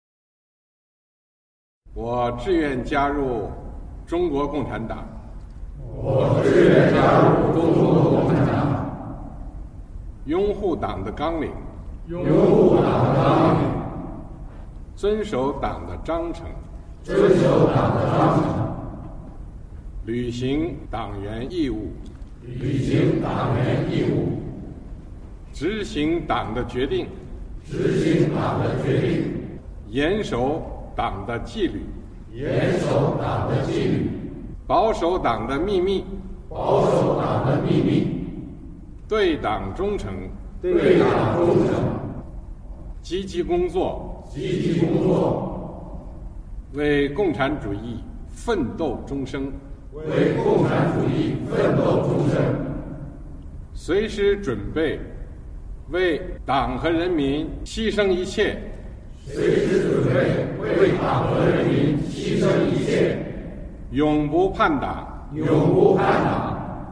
● 和总书记一起重温入党誓词